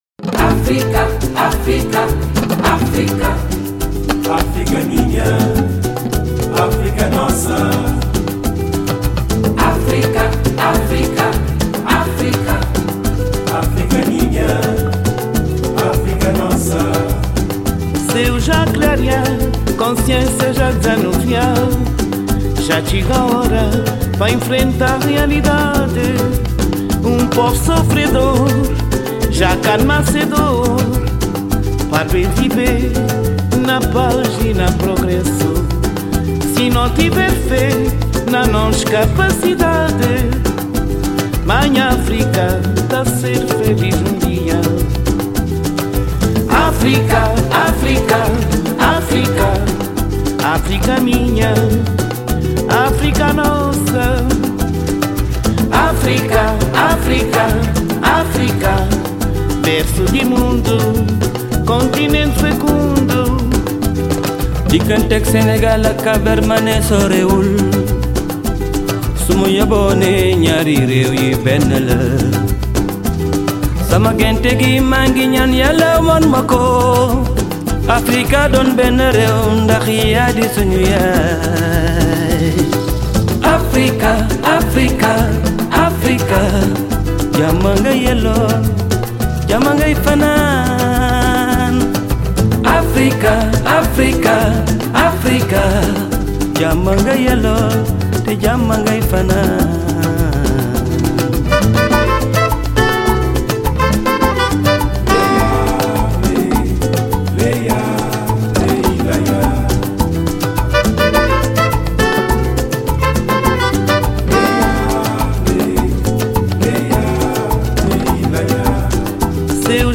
Голос завораживает ...